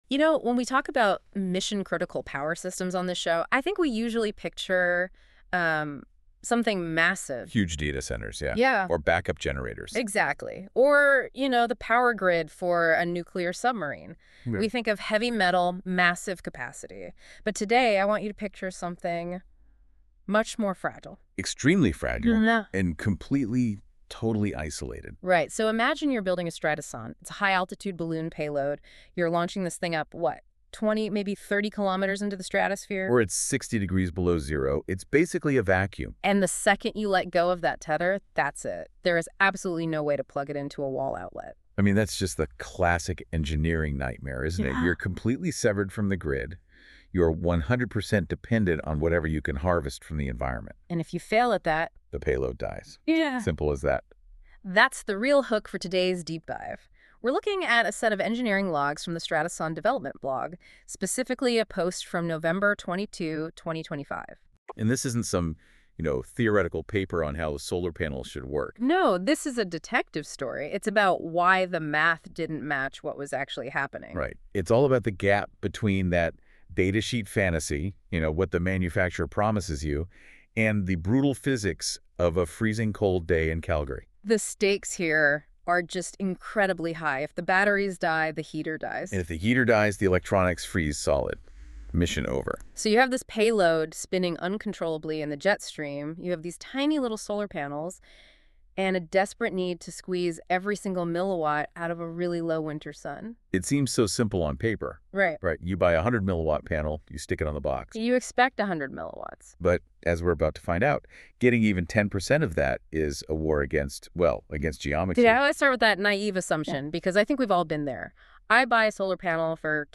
🎧 Listen to this article: NotebookLM Podcast An AI-generated audio discussion created by Google’s NotebookLM